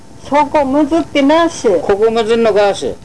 この町のことばをお聴きいただけます